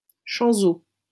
-Chanzeaux.wav Audio pronunciation file from the Lingua Libre project.